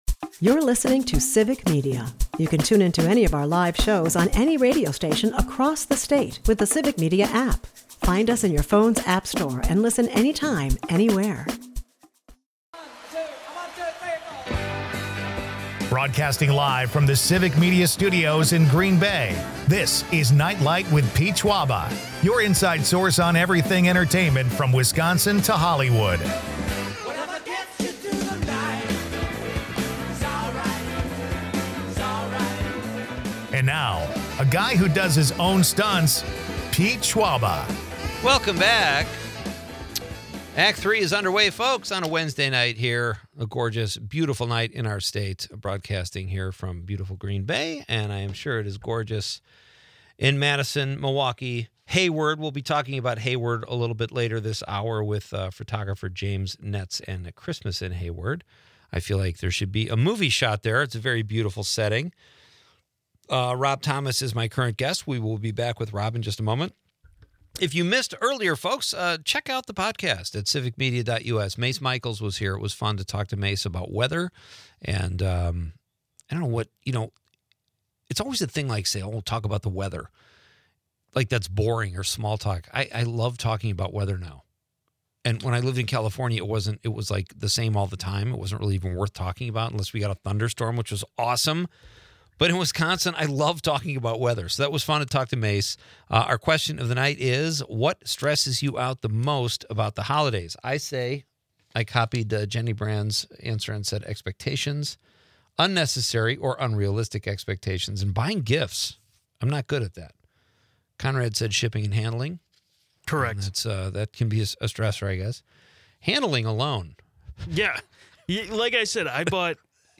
Listeners contribute to the lively discussion with their holiday stressors, sparking a conversation on the pressures and pleasures of the season. A humorous dive into holiday traditions, movies, and the charm of small-town festivities makes